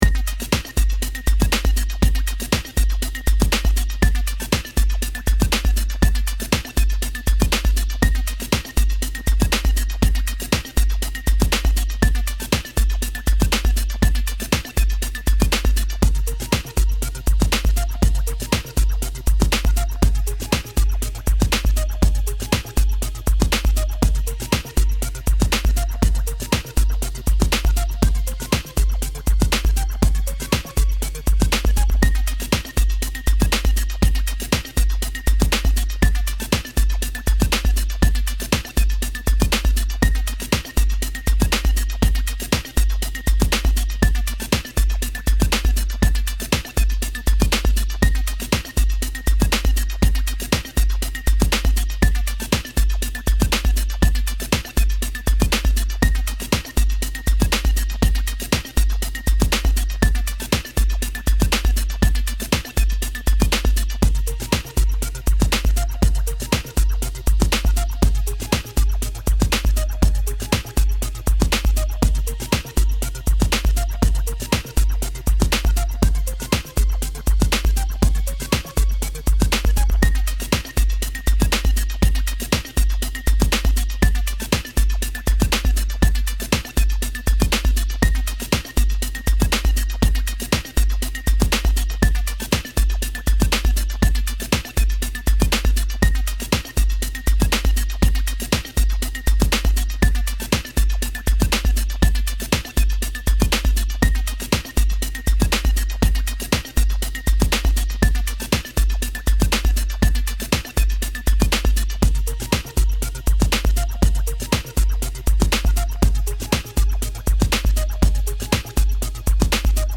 クールな雰囲気なので、少しだけ緊迫したシーンや謎解きのシーンなどで使えそう。 素材を繋ぎ合わせれば簡単にループできます。